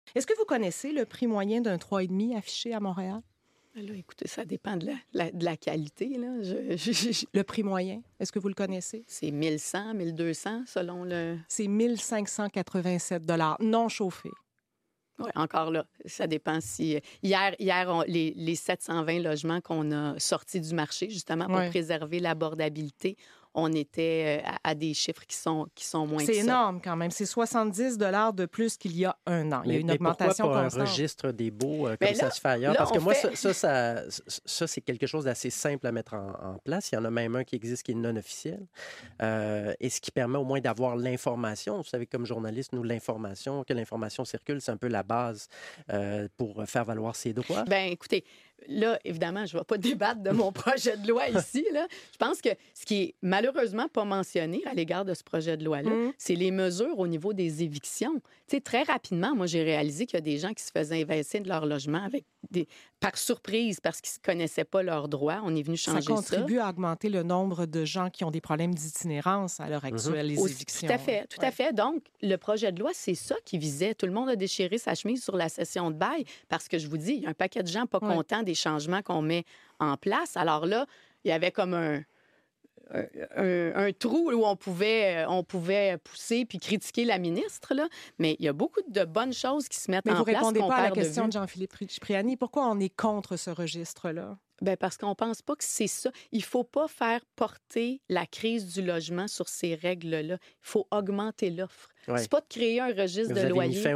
Quel est le prix moyen sound effects free download By rc.ohdio 104 Downloads 23 months ago 103 seconds rc.ohdio Sound Effects About Quel est le prix moyen Mp3 Sound Effect Quel est le prix moyen d'un trois et demi à Montréal? 🏘 Au micro de l'émission «Tout peut arriver», la ministre responsable de l'Habitation
🎧 Rattrapez l'entrevue sur RC OHdio (11 nov.)